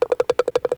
Golf_Ball_Goes_In_Loop.ogg